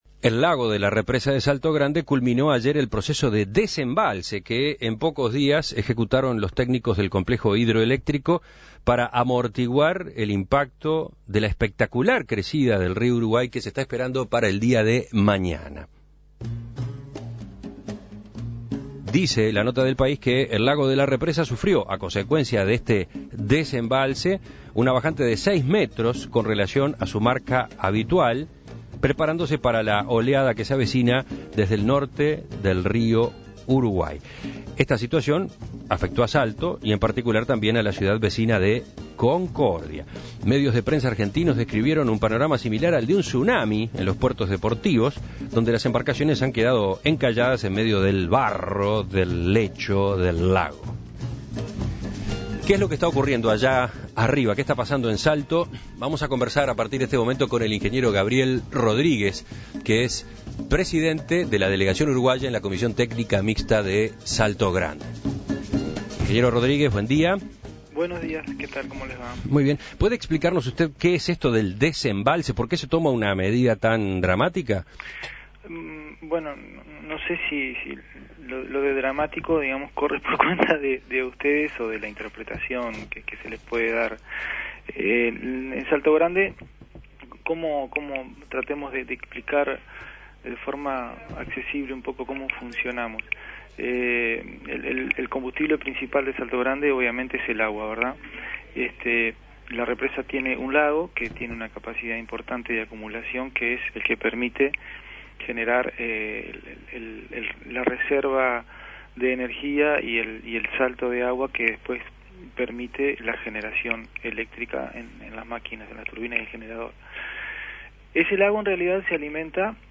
(emitido a las 8.40 hs.)